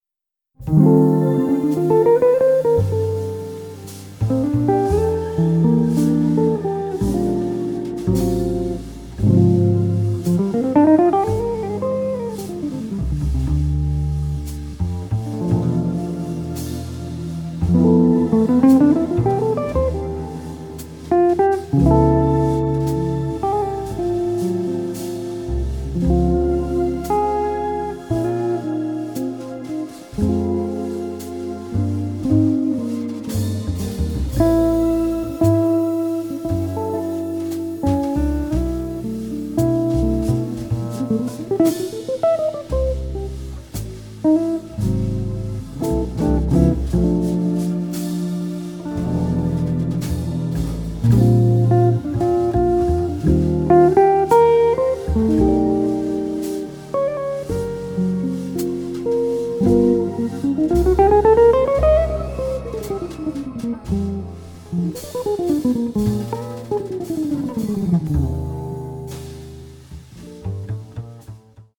bass
drums
guitar